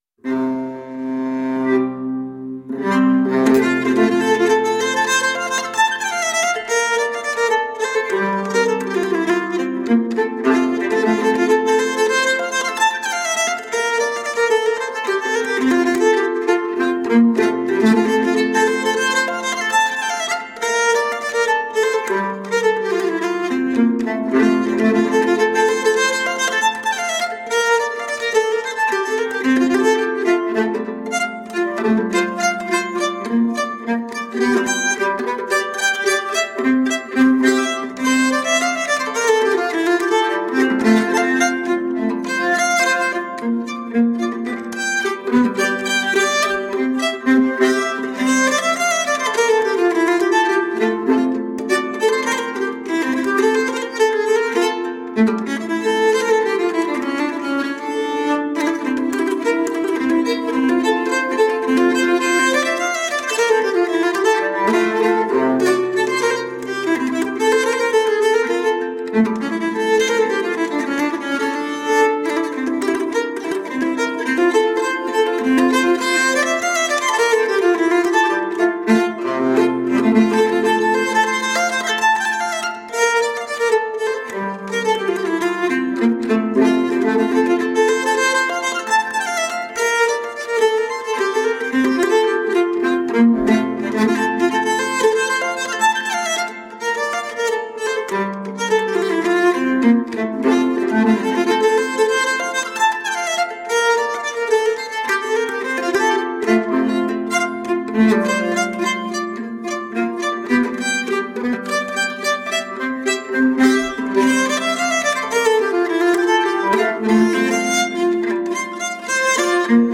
Timeless and enchanting folk music for the soul.
Tagged as: World, Folk, Harp